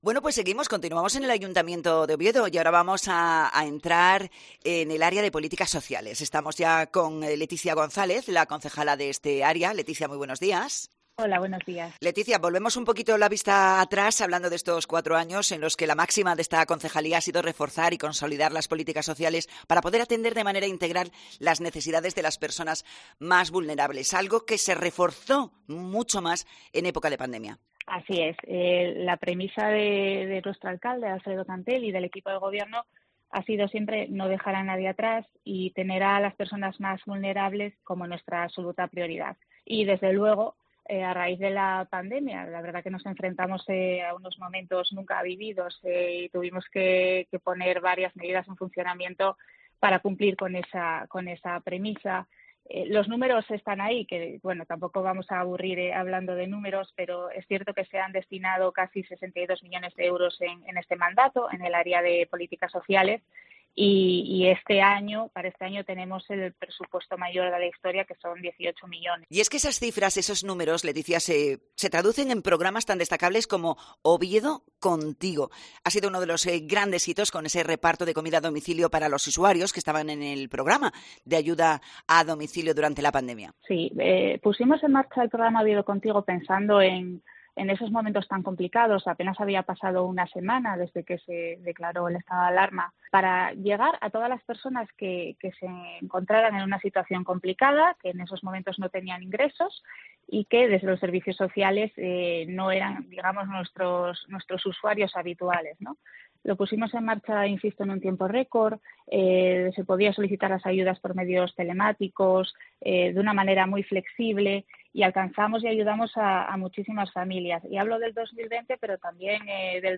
Entrevista a Leticia González, concejala de Políticas Sociales de Oviedo